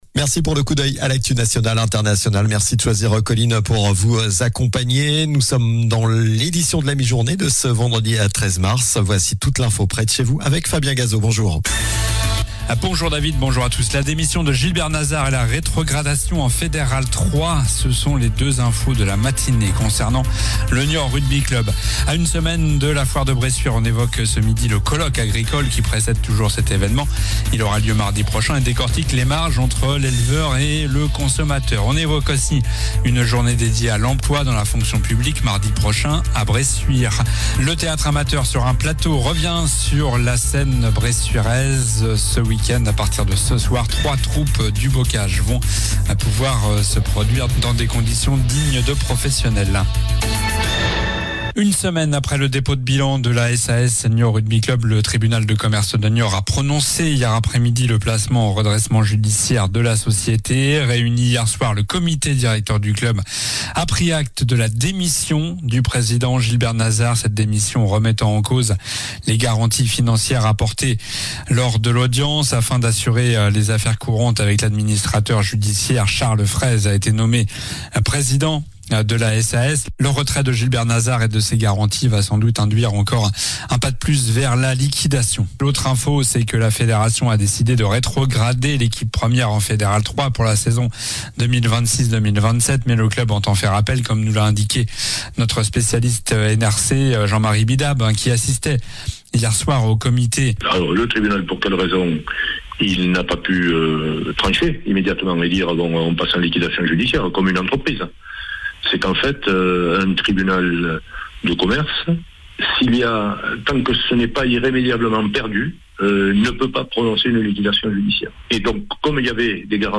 Journal du vendredi 13 mars (midi)